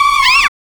1905R SYN-FX.wav